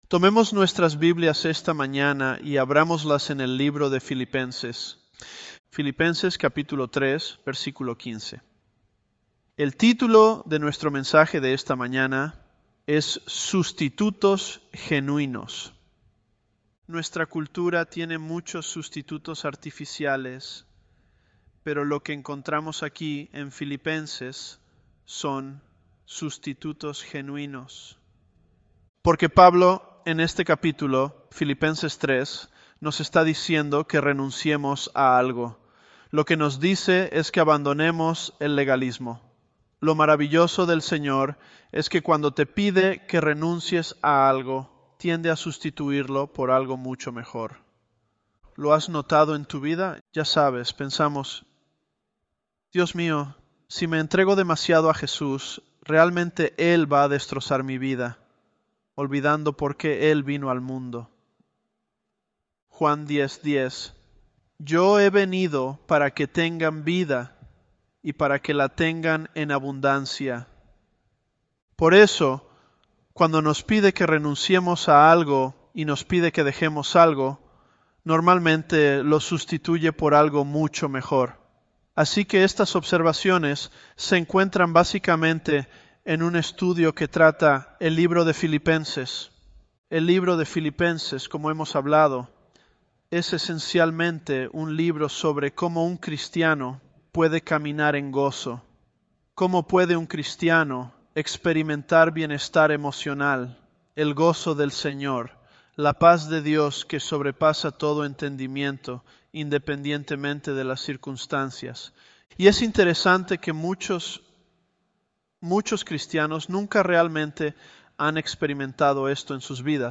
Elevenlabs_Philippians009.mp3